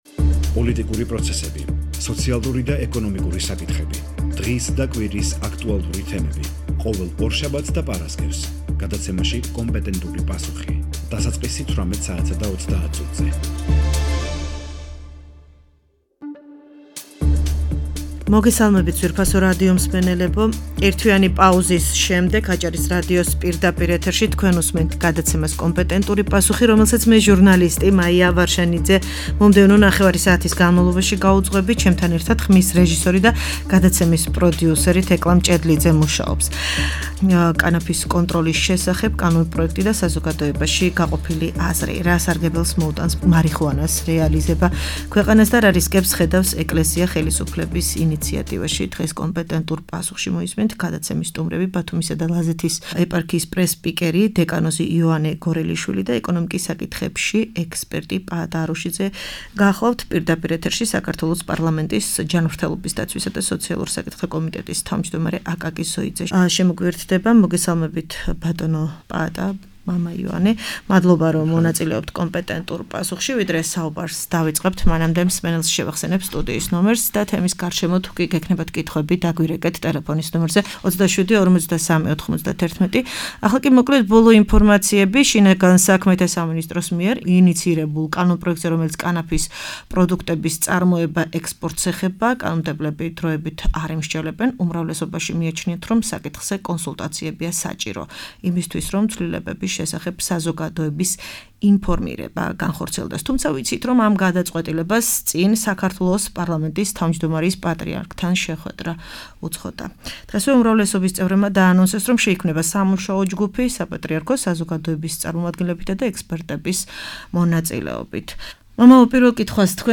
პირდაპირ ეთერში საქართველოს პარლამენტის ჯანმრთელობის დაცვისა და სოციალურ საკითხთა კომიტეტის თავმჯდომარე აკაკი ზოიძე ჩაერთო.